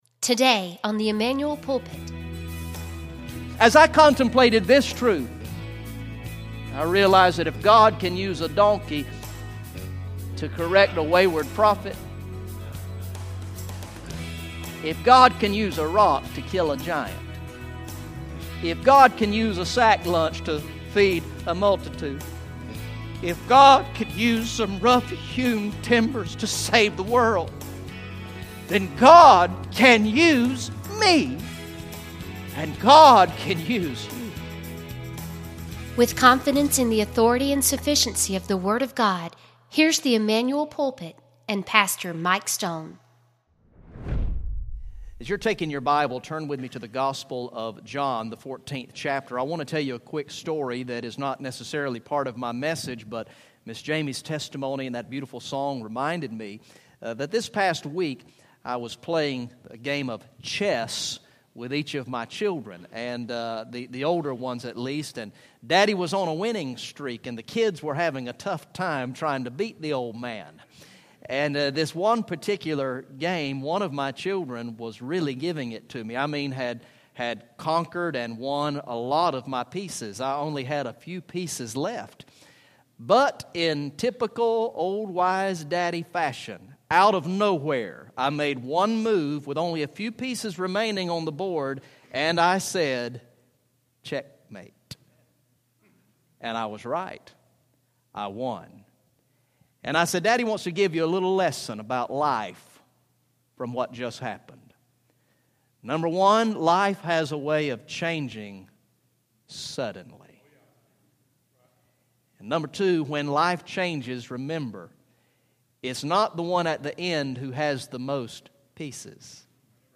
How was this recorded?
Message #52 from the sermon series through the gospel of John entitled "I Believe" Recorded in the morning worship service on Sunday, January 3, 2016